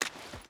Footsteps / Water / Water Run 3.ogg
Water Run 3.ogg